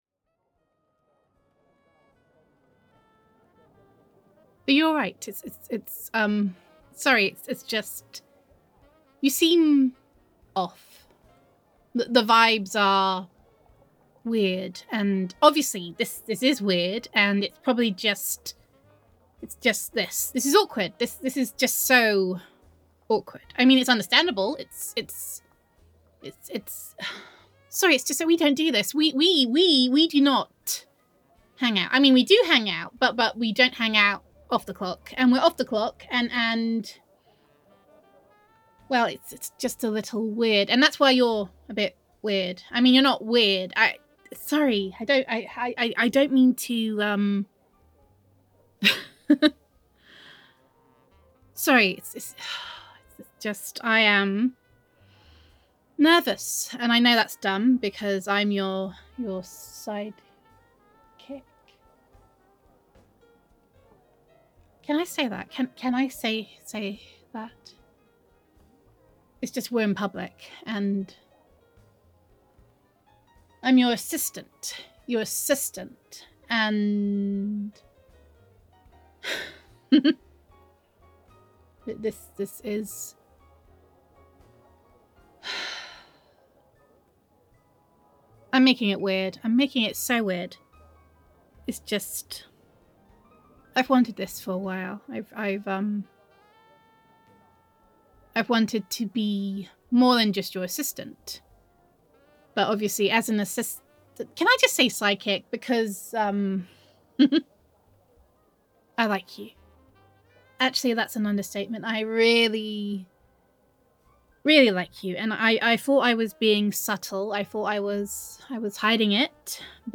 [F4A] Perhaps This Is My Origin Story [Sidekick Roleplay][Superhero Listener][Love Confession][Betrayal][Jealousy][Friends to Enemies][You Did This][This Is All Your Fault][Turning Into a Supervillain][Biokinesis][Gender Neutral][Your Sidekick May Think She’s Going on a Date, but in Reality, This Is the Culmination of a Six-Month Plot Against Her]